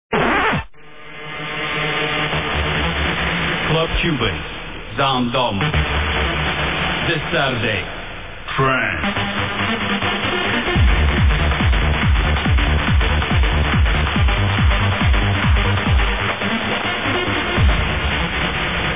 Commercial Tune